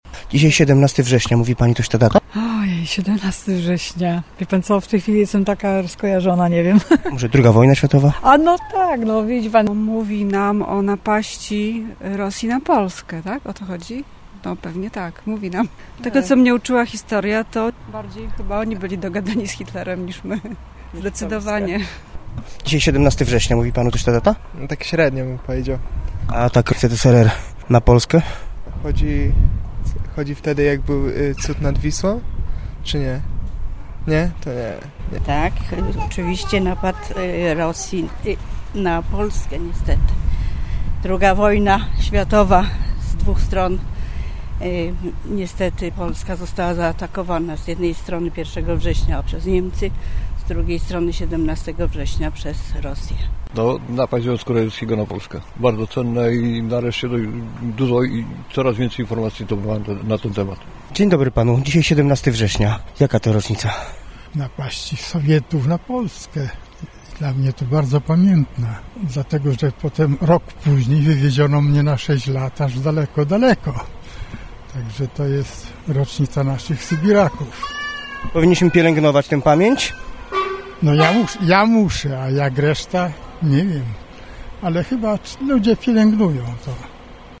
Dziś, w rocznicę tamtych wydarzeń, spytaliśmy zielonogórzan, co wiedzą o sowieckiej agresji na Polskę: